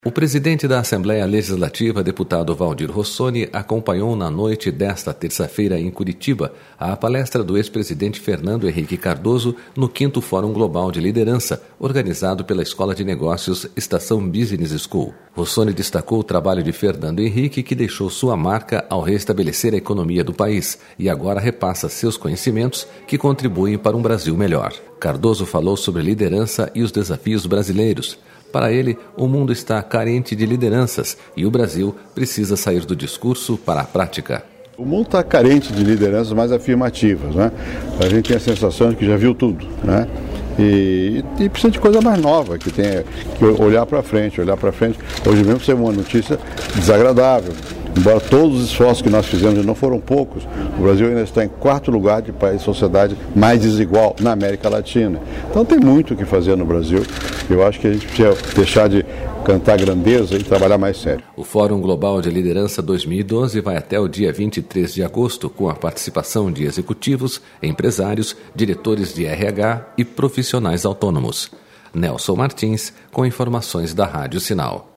SONORA FHC